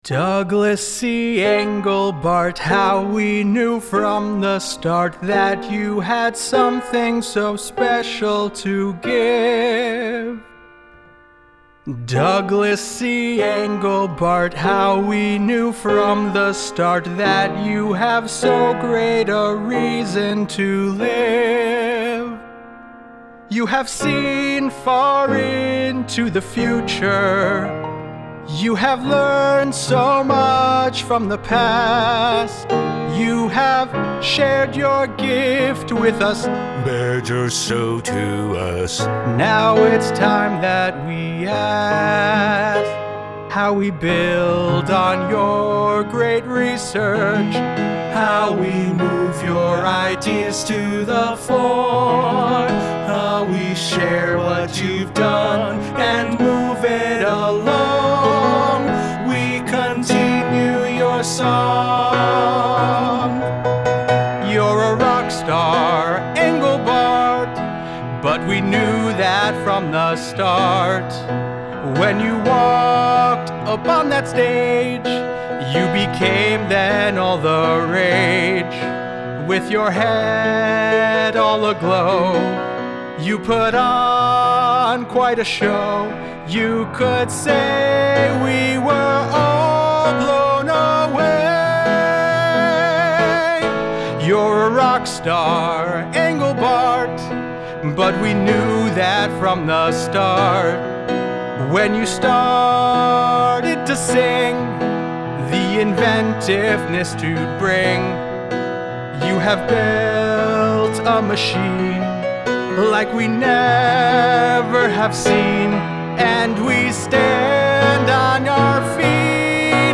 Note: The following includes charts, context, and rough demos (some rougher than others).
(scene 4) Engelbart (duet):